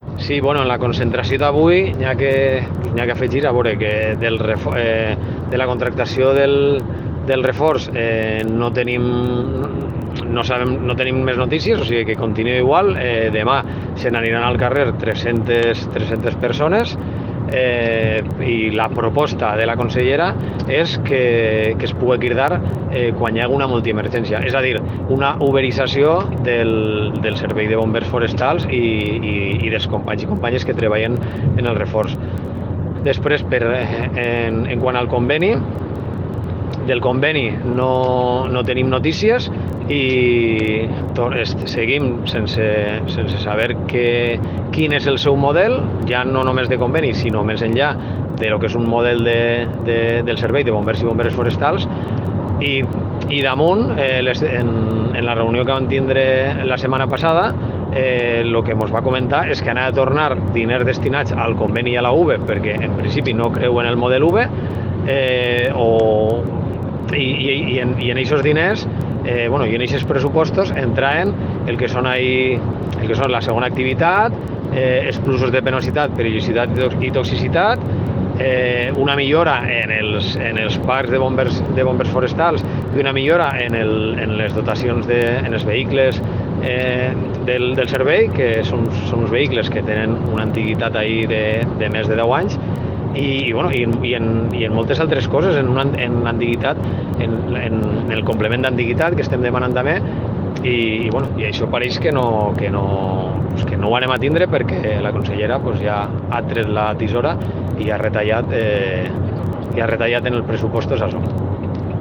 Declaracions